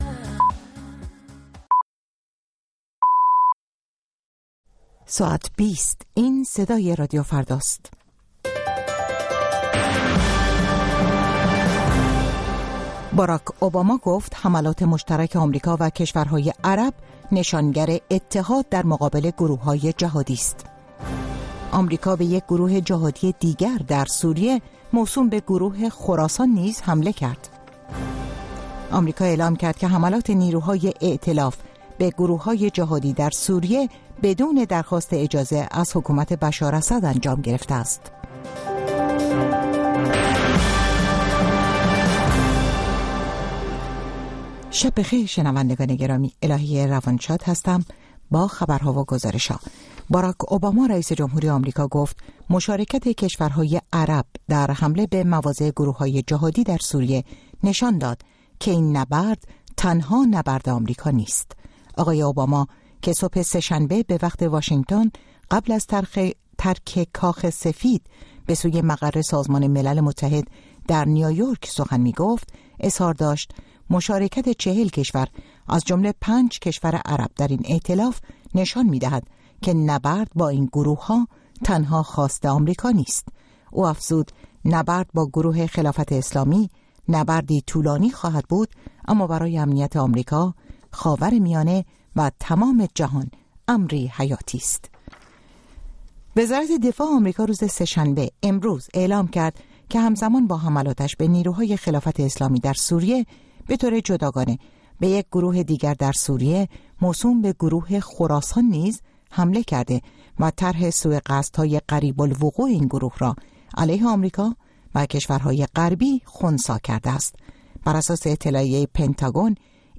مشروح خبرهای ايران و جهان، بحث و بررسيهای مهمترين رويدادهای خبری روز در گفتگو با خبرسازان، کارشناسان و تحليلگران